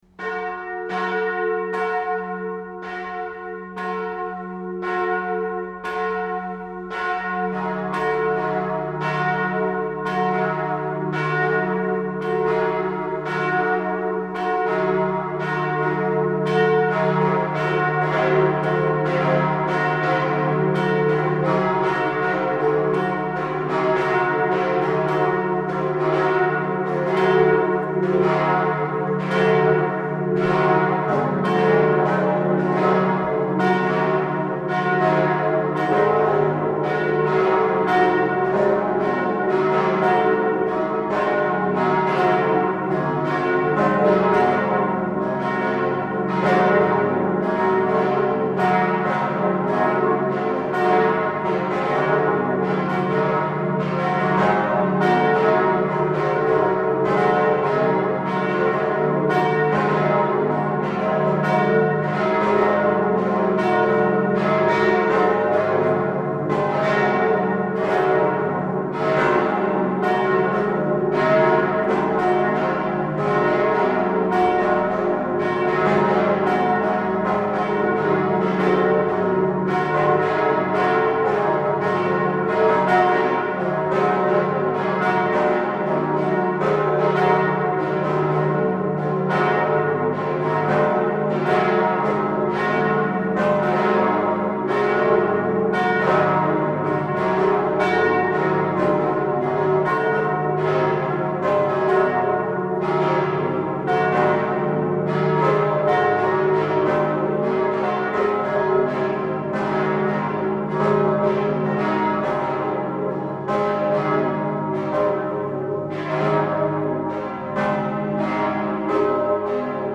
Die Glocken von Mondsee klingen so
Mondsee_Glocke_VG_MP3